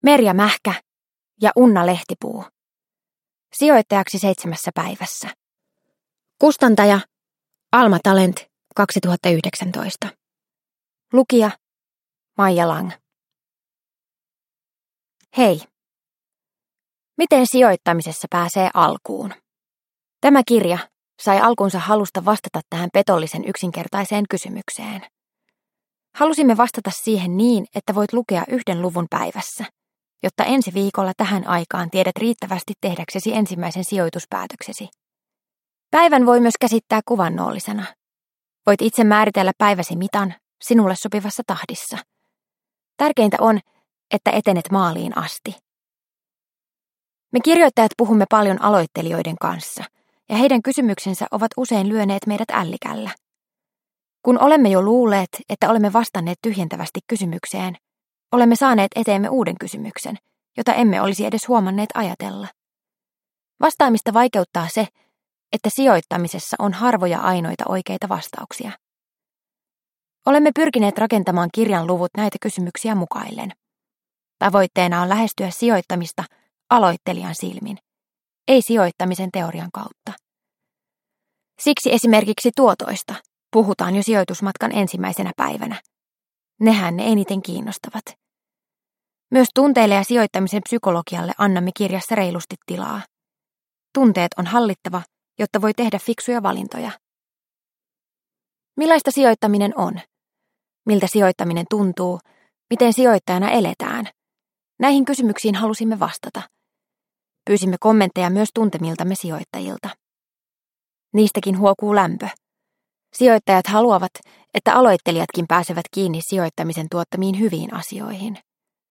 Sijoittajaksi 7 päivässä – Ljudbok – Laddas ner